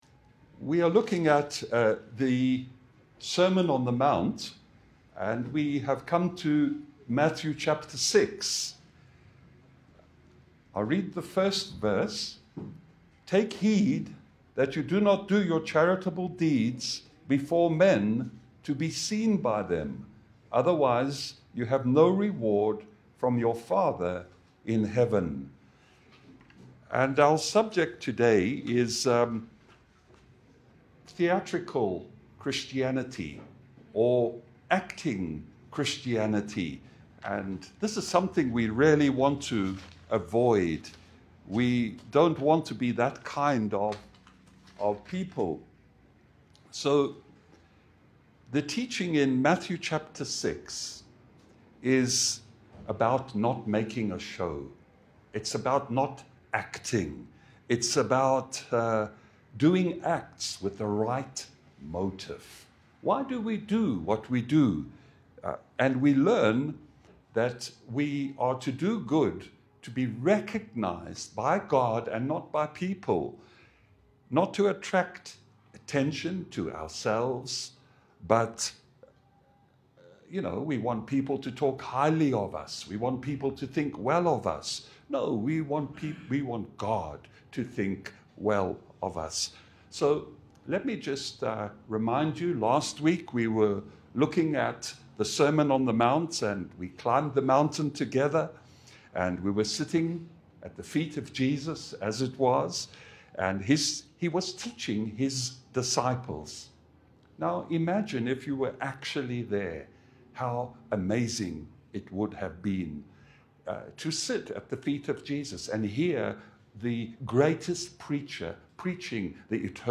Passage: Matthew 6:1 Service Type: Sunday Bible fellowship Download Files Notes « Wisdom from Above Where do wars and fights come from?